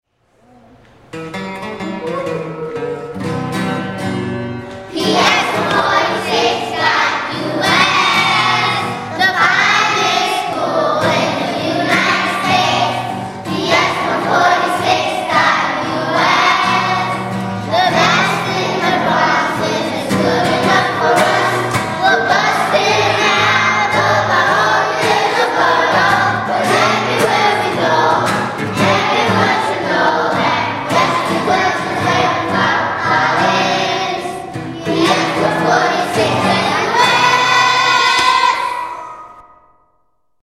Click here to hear with singing